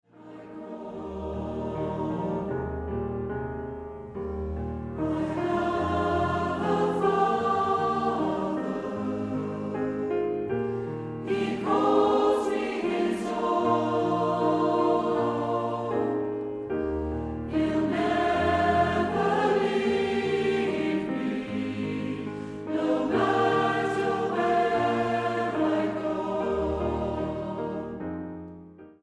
STYLE: Classical